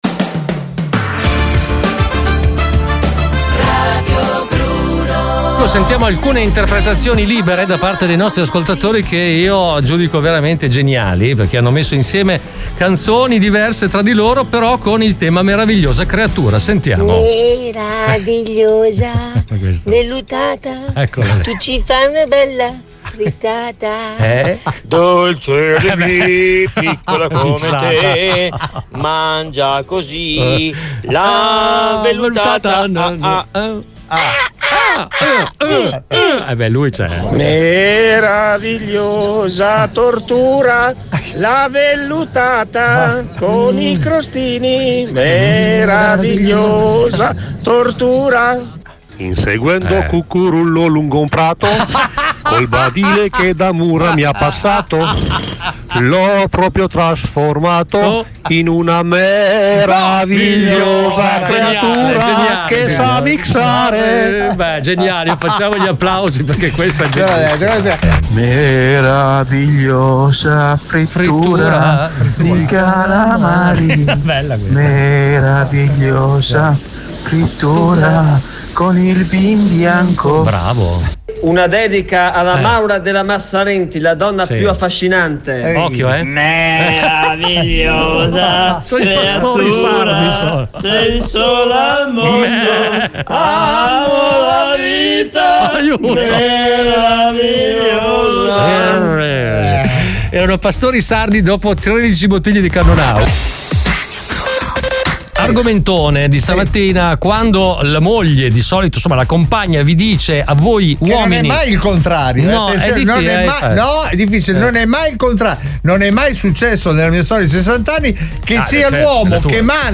Nella puntata di oggi, tra le grandi doti degli ascoltatori, il canto.